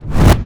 casting_charge_whoosh_buildup3.wav